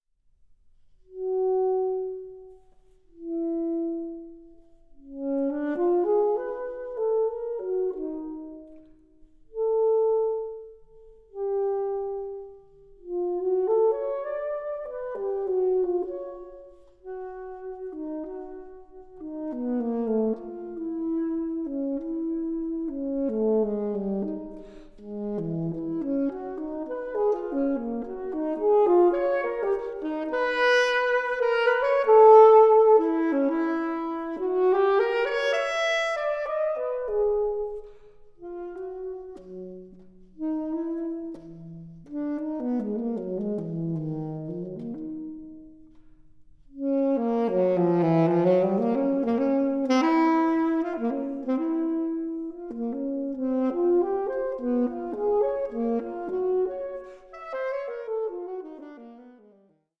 SOLO SAXOPHONE
Saxophonist